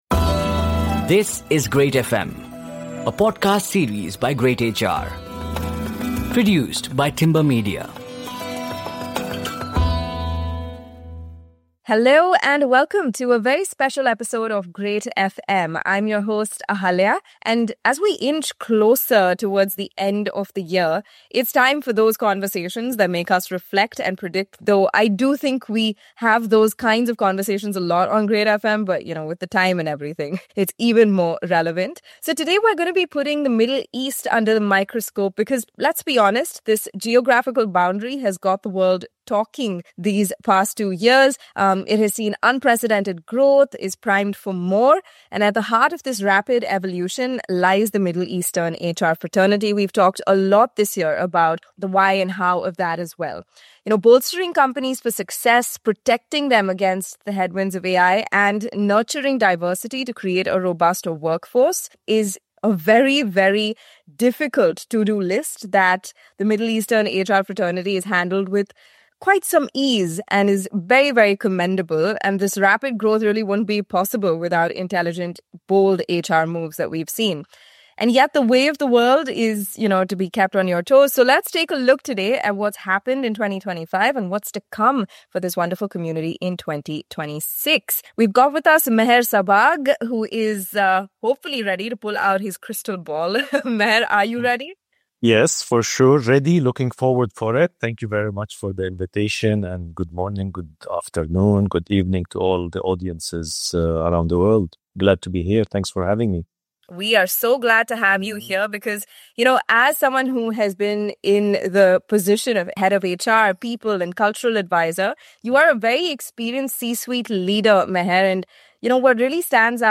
He also highlights the top priorities, skill areas and shifts HR teams must prepare for in 2026, along with a few bold predictions about the future of work in the Middle East. Tune in for a crisp, forward-looking conversation designed to help HR professionals stay ready for what’s coming next.